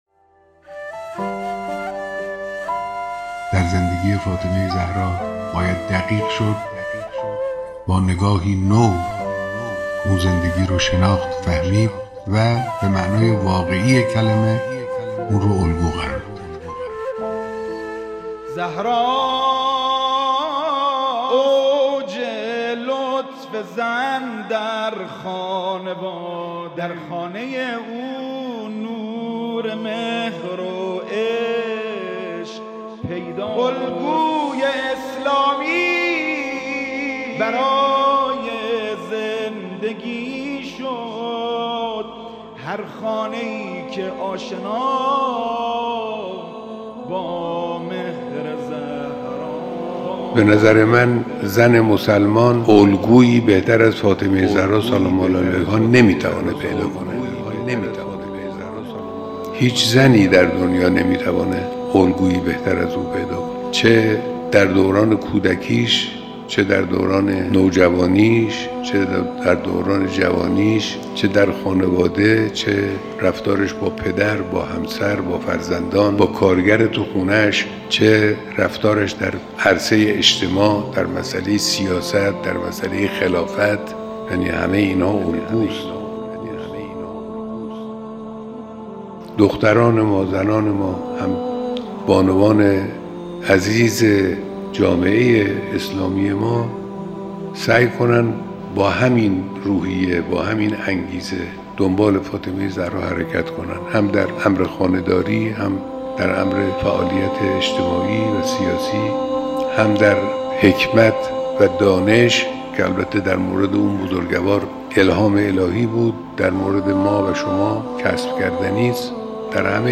بیانات رهبر انقلاب که در این صوت میشنوید: